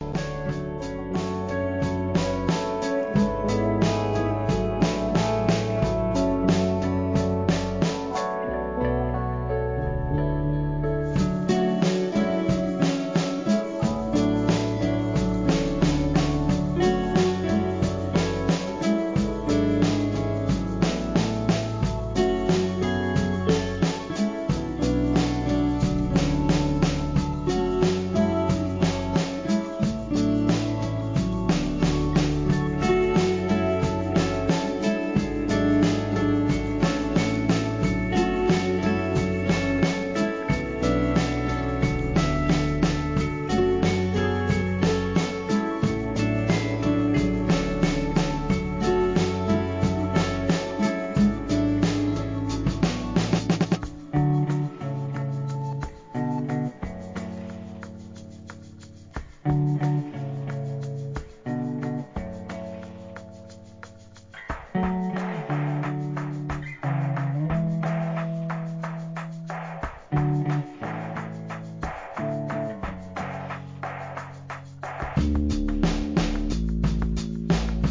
温かく心地良いサウンドはAmbient名作中の名作!!!